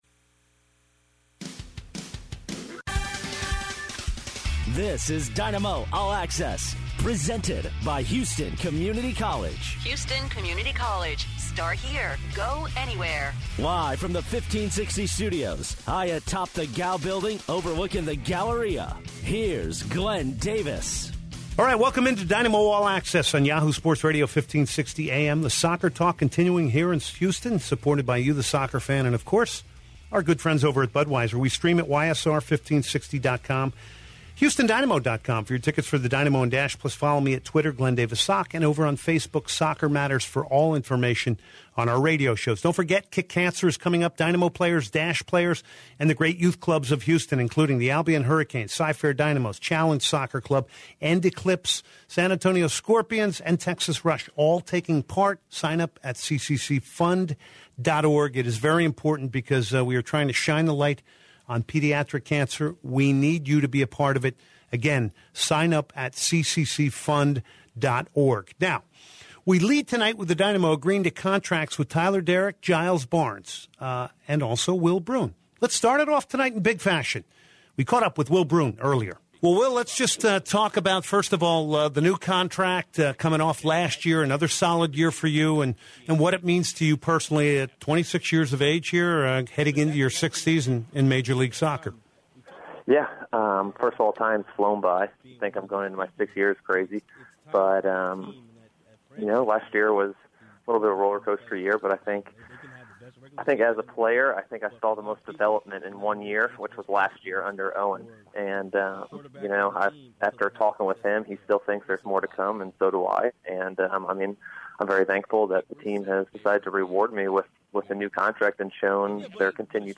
Guests Will Bruin and Oliver Luck. Owen Coyle interview part 1 and 2.